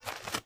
STEPS Dirt, Walk 29.wav